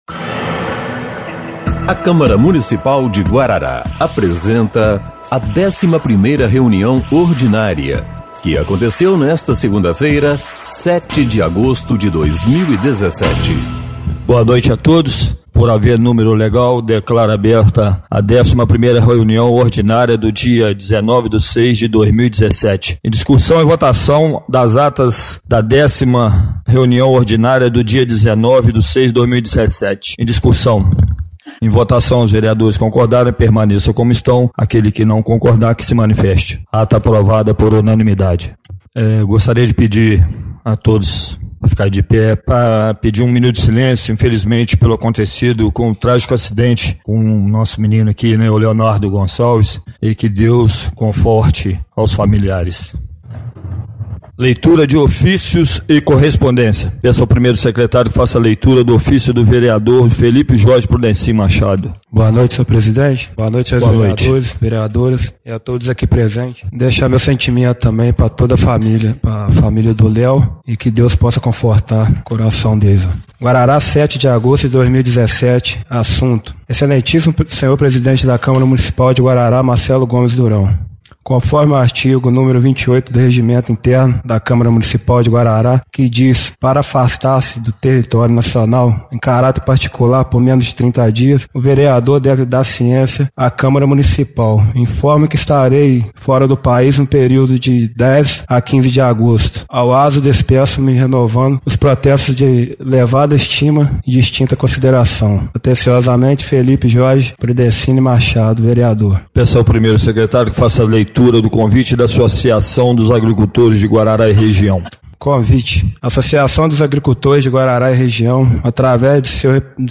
11ª Reunião Ordinária de 07/08/2017